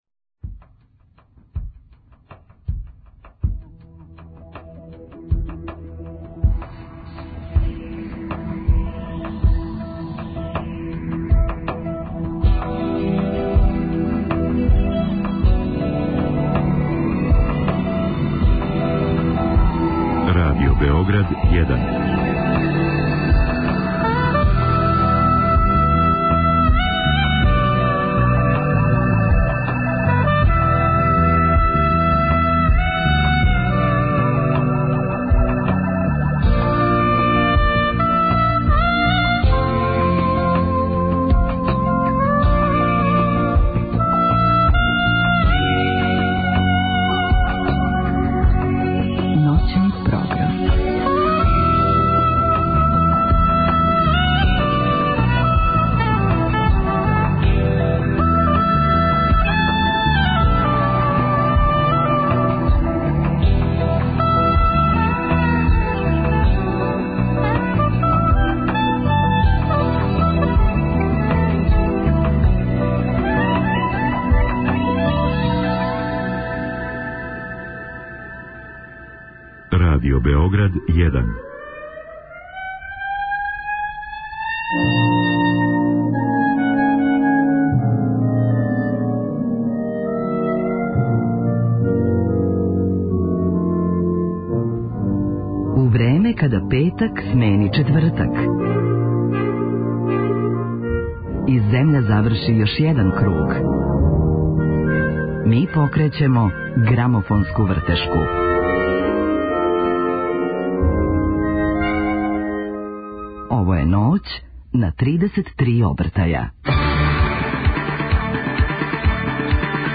Наш гост у Ноћи на 33 обртаја биће кантаутор Срђан Марјановић. Говорићемо о његовој каријери, плочама, али и о престојећем концерту.